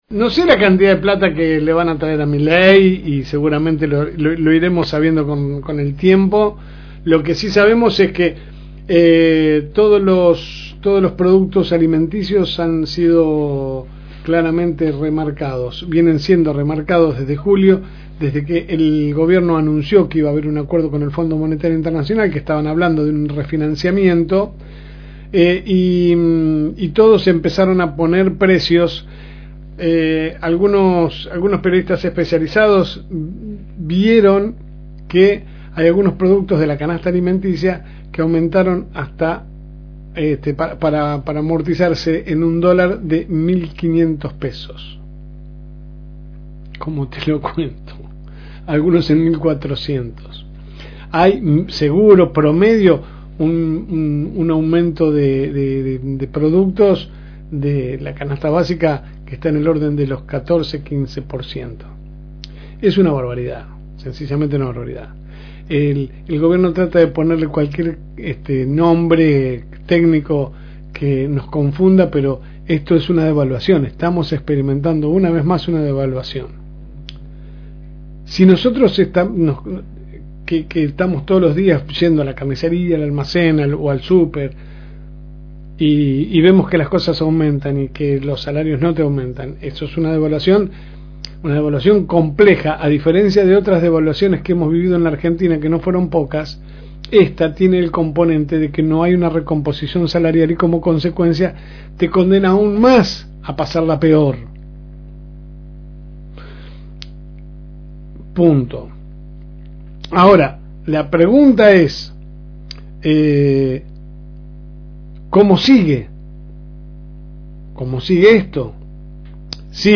Compartimos con ustedes la editorial de la semana